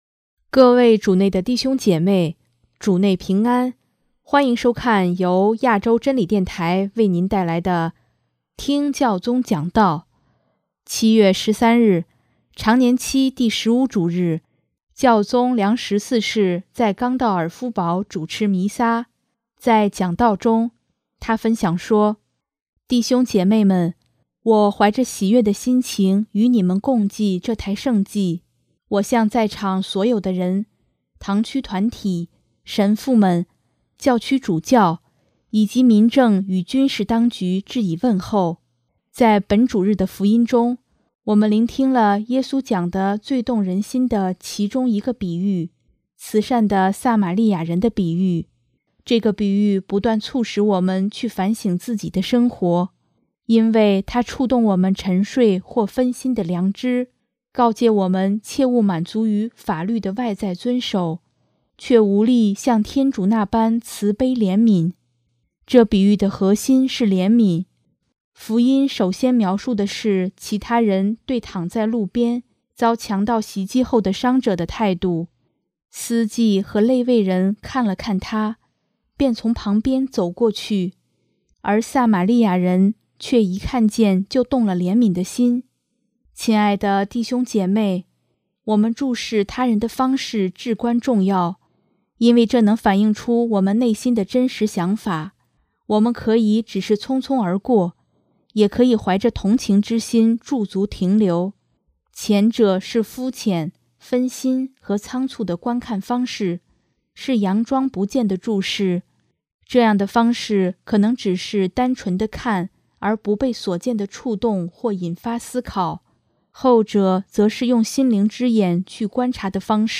7月13日，常年期第十五主日，教宗良十四世在冈道尔夫堡主持弥撒，在讲道中，他分享说：